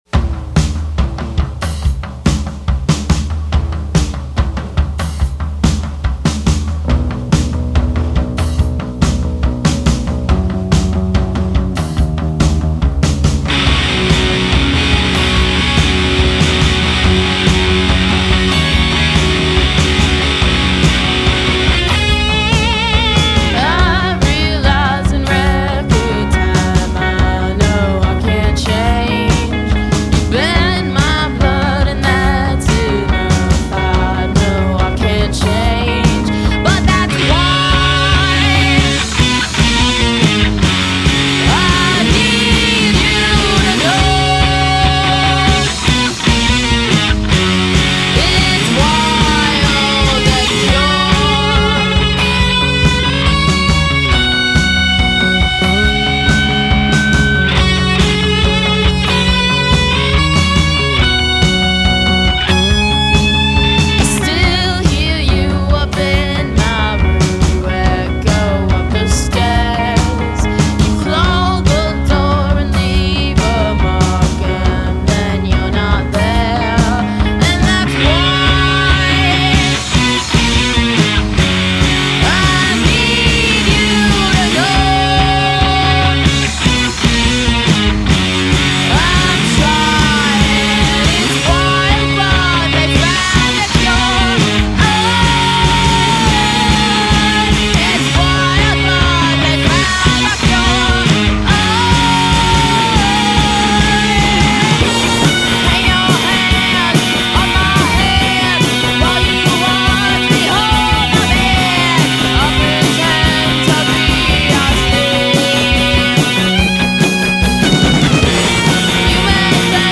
guitar
bass
punk prowess and hard-rock snarl